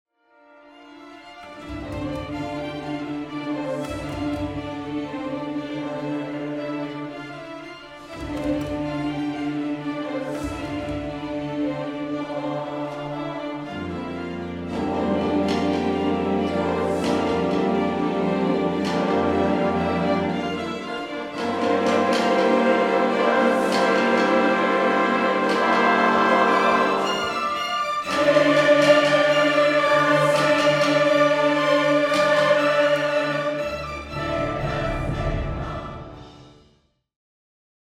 soprano and mezzo-soprano soloists, chorus